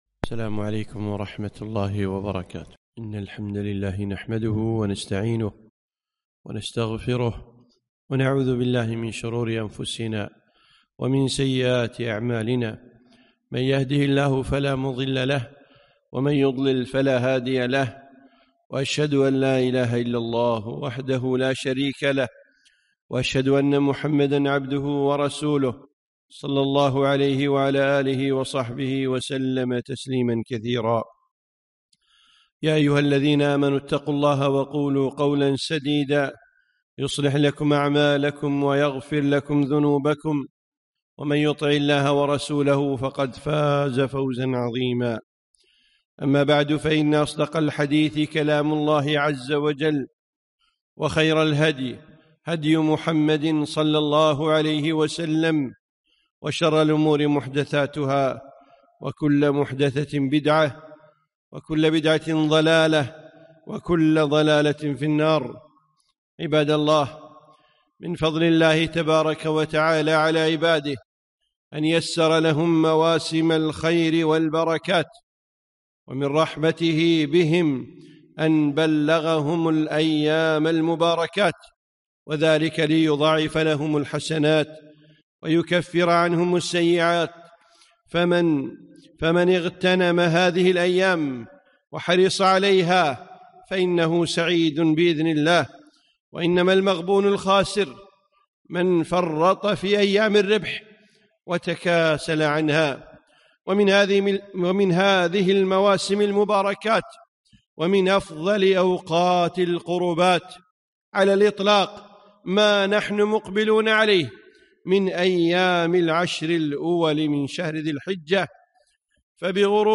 خطبة - استعدوا لأيام العشر